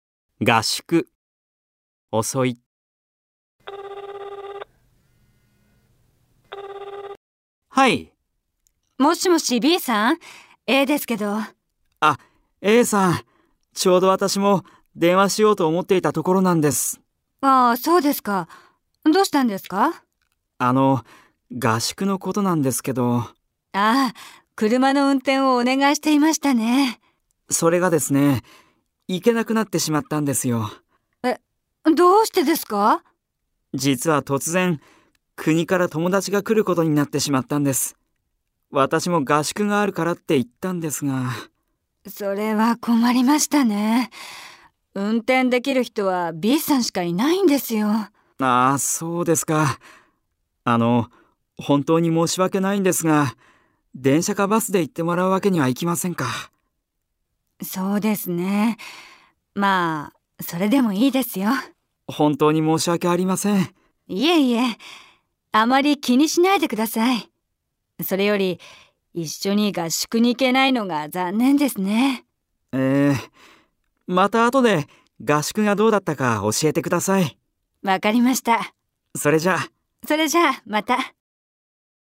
新版では、「復習しよう！」「練習しよう！」の解答例と、「機能別上級会話表現」を別冊として付けたほか、「復習しよう！」の会話文の音声を収録。